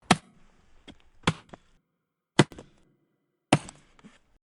Small Tree Chop
Small Tree Chop is a free sfx sound effect available for download in MP3 format.
Small Tree Chop.mp3